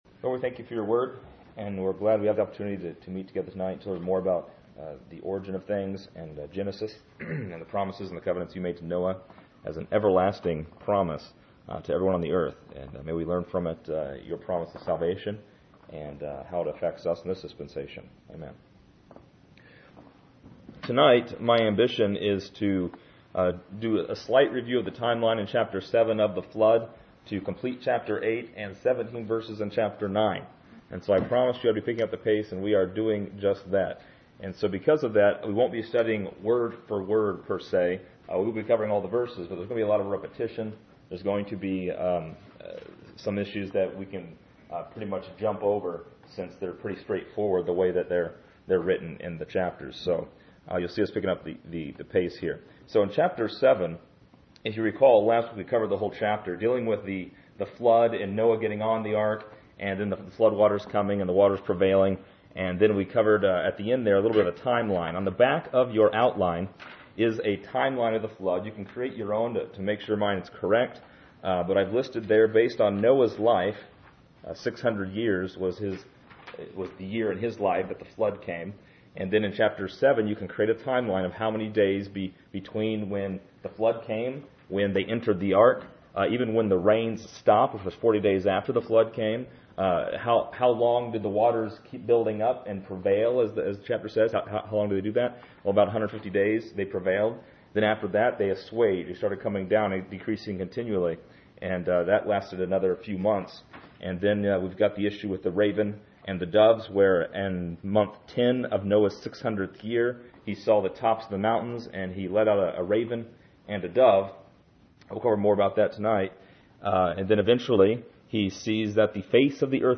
This lesson is part 18 in a verse by verse study through Genesis titled: Noah’s Covenant.